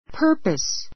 purpose A2 pə́ː r pəs パ ～パ ス 名詞 目的, つもり for this purpose for this purpose この目的で What's the purpose of your visit?